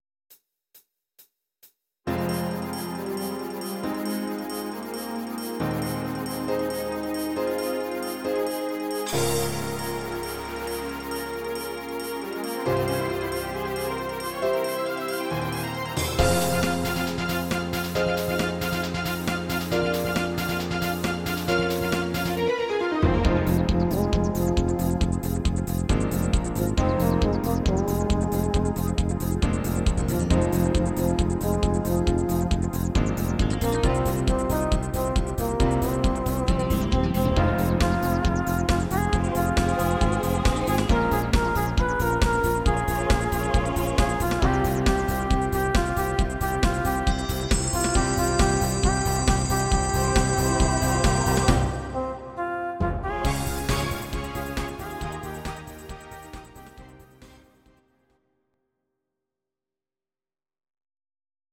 These are MP3 versions of our MIDI file catalogue.
Your-Mix: Disco (724)